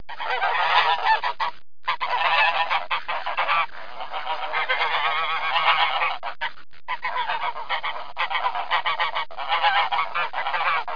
دانلود آهنگ غاز برای کودکان از افکت صوتی انسان و موجودات زنده
دانلود صدای غاز برای کودکان از ساعد نیوز با لینک مستقیم و کیفیت بالا
جلوه های صوتی